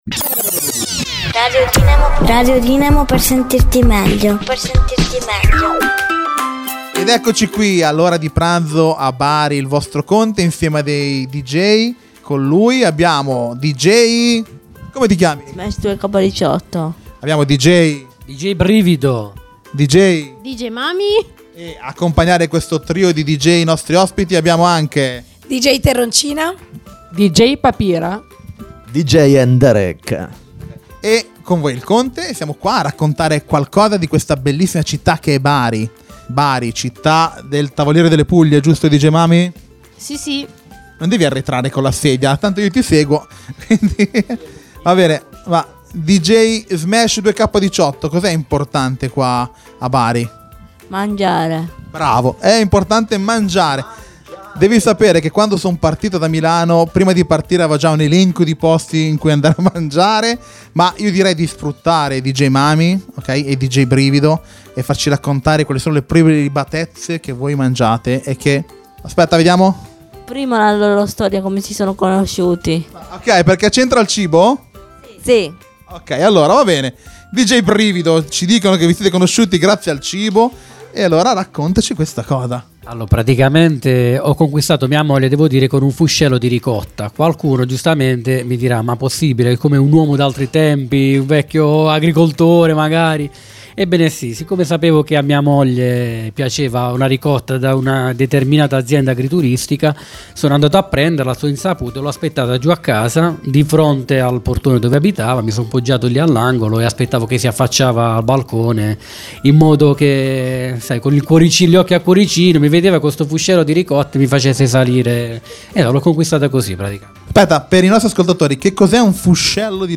MINI INETRVISTA!!
MININTERVISTA-GENITORI-Mixdown-1.mp3